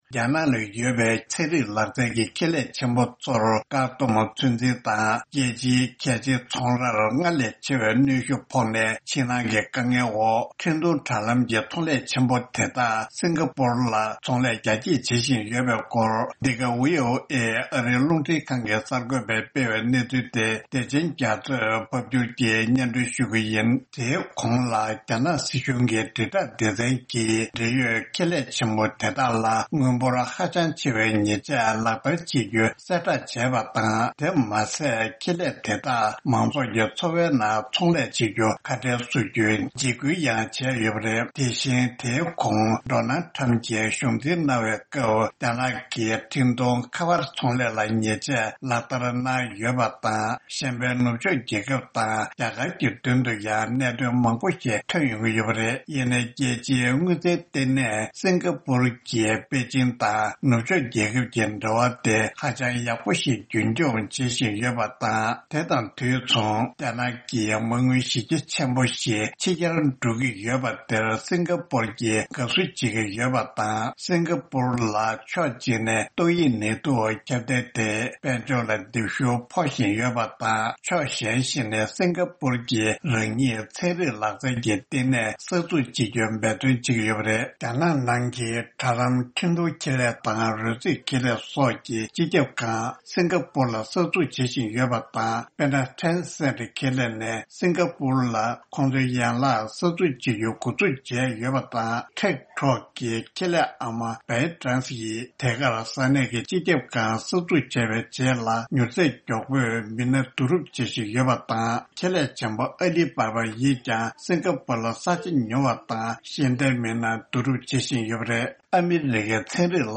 ཨ་རིའི་རླུང་འཕྲིན་ཁང་གི་གསར་འགོད་པས་སྤེལ་བའི་གནས་ཚུལ